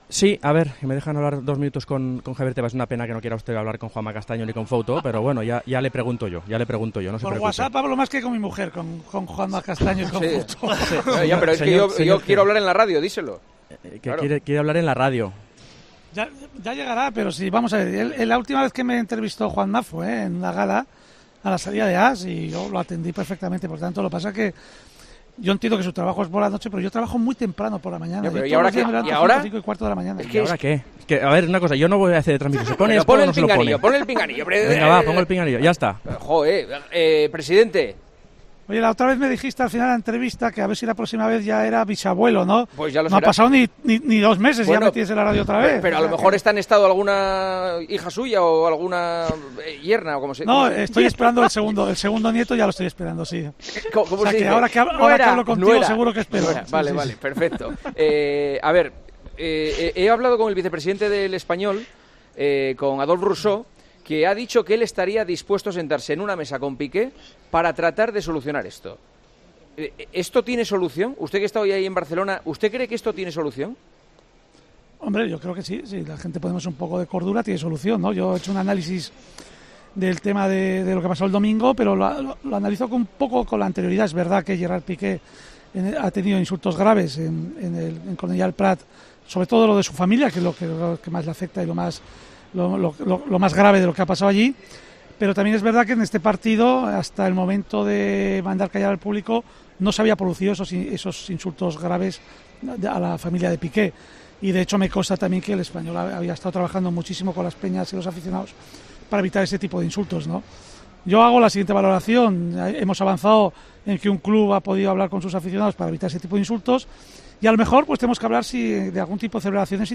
Javier Tebas atendió a Juanma Castaño este lunes desde la gala de 'El Mundo Deportivo'.